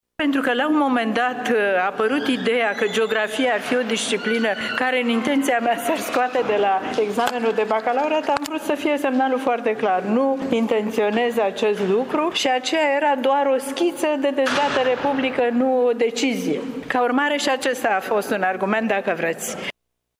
Este asigurarea pe care a dat-o astăzi ministrul Educației, Ecaterina Andronescu, prezentă la Târgu Mureș la festivitatea de inaugurare a celei de-a 39-a ediții a Olimpiadei Naționale de Geografie.